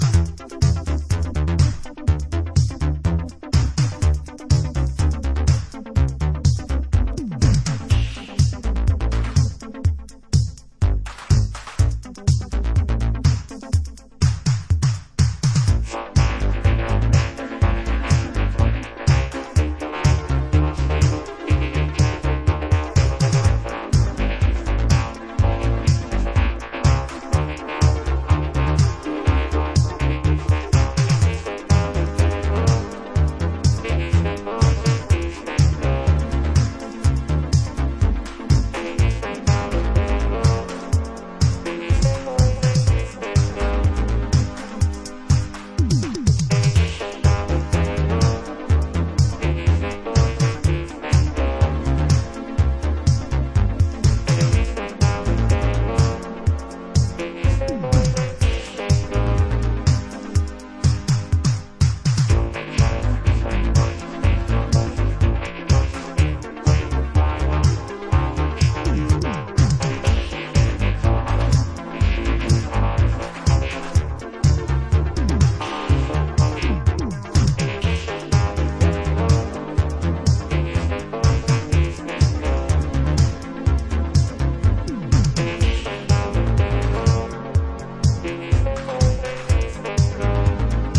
beautiful sun drenched italo house track